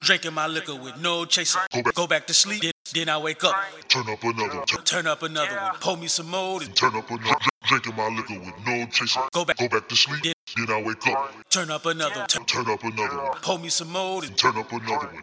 Tag: 126 bpm Trap Loops Strings Loops 2.56 MB wav Key : F